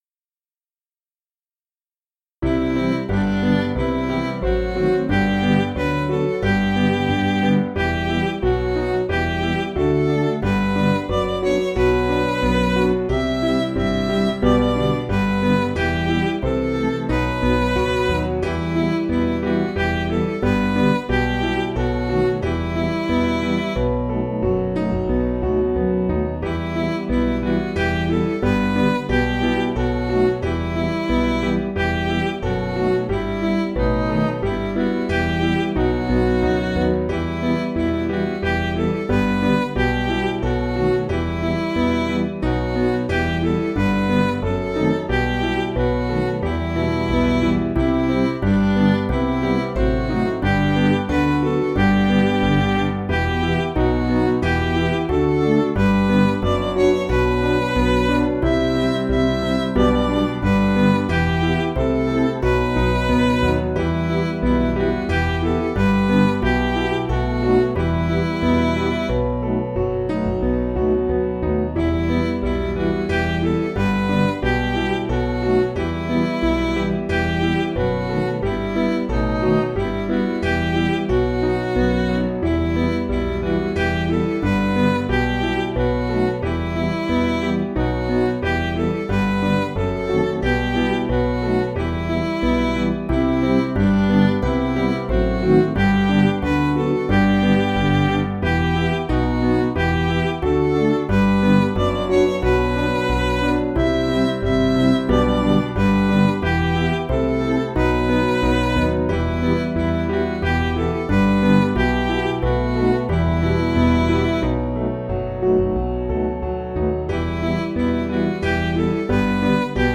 Piano & Instrumental
(CM)   3/Em